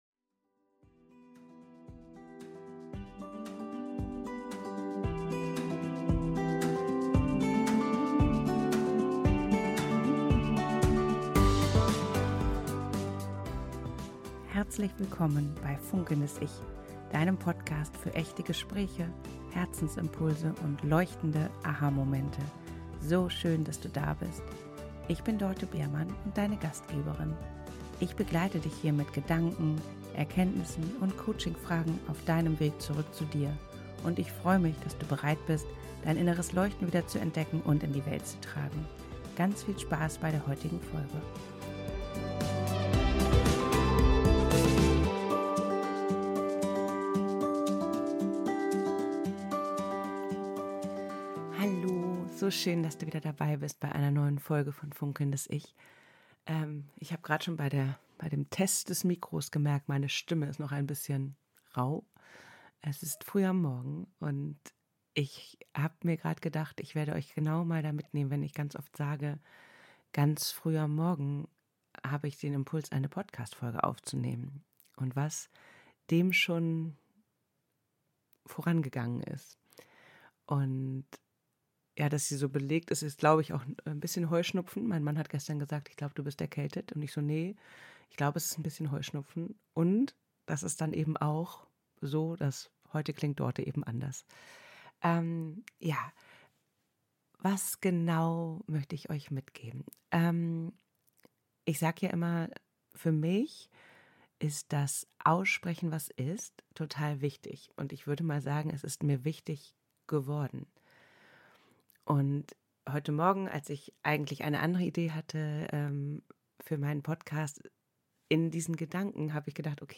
In der heutigen Solo-Folge teile ich mal wieder einige meiner Gedanken mit dir. Ich erzähle meinen Weg: raus aus dem nächtlichen Grübeln hin zum Aufschreiben und nun zum Aussprechen - und dem Teilen meiner vielen Gedanken mit dir in diesem Podcast.